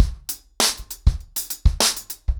DrumkitRavage-100BPM_1.9.wav